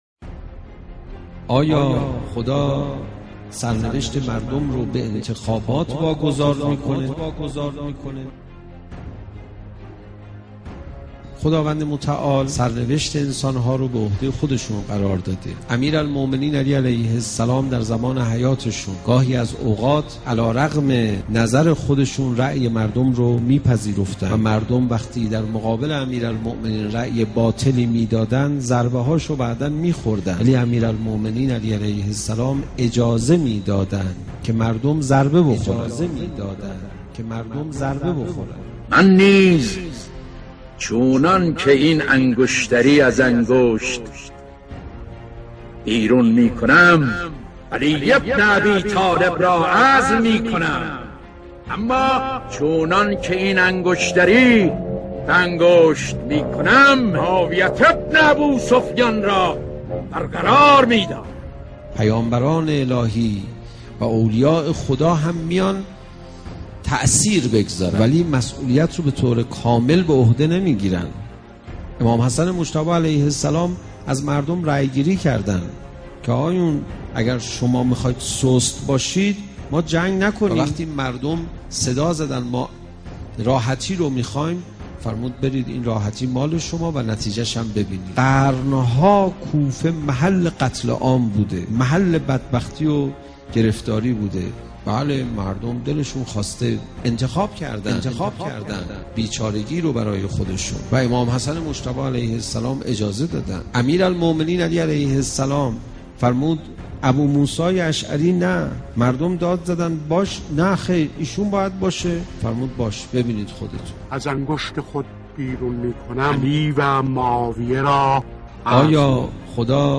تولید: بیان معنوی مدت زمان: 04:10 دقیقه   منبع: رباط کریم- مسجد امام محمد باقر(ع) متاسفانه مرورگر شما، قابیلت پخش فایل های صوتی تصویری را در قالب HTML5 دارا نمی باشد.